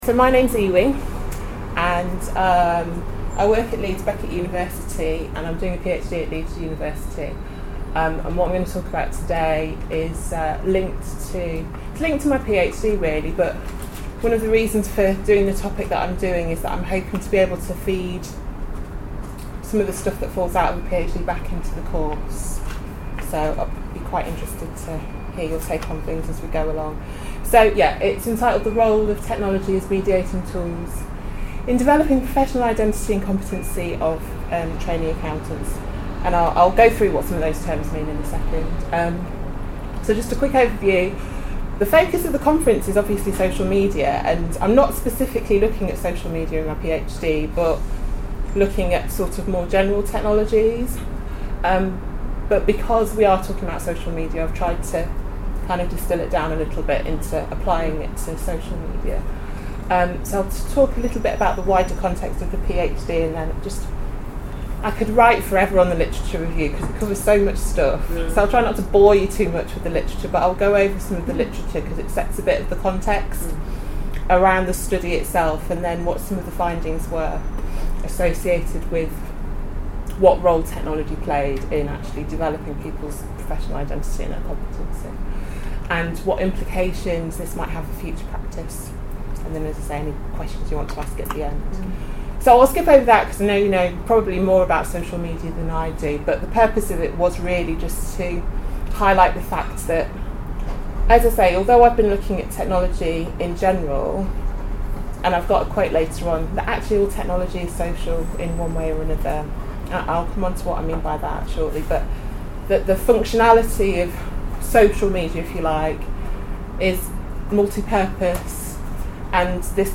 Download (466kB) Presentation Available under License In Copyright .